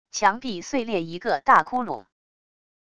墙壁碎裂一个大窟窿wav音频